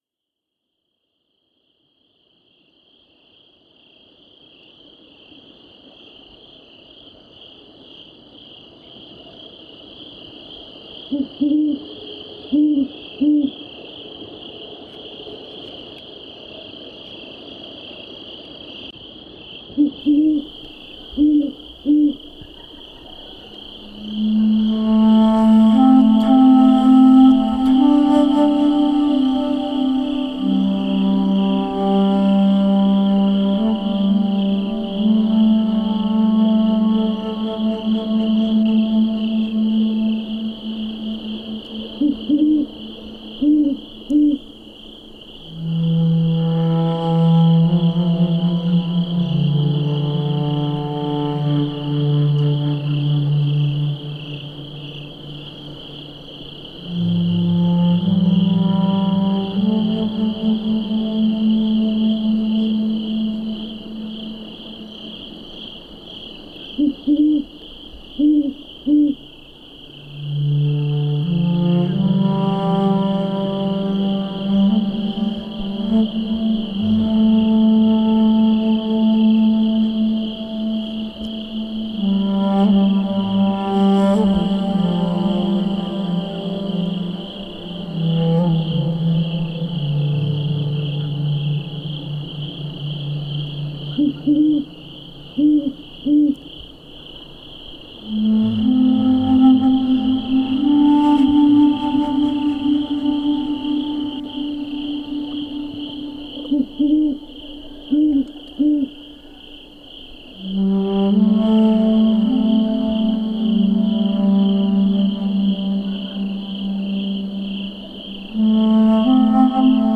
A few nights ago an owl came and sang near our house.
I recorded her the best I could and today added some bass flute to join with the night chorus and the fluting of the owl.
Autumn-Owl.mp3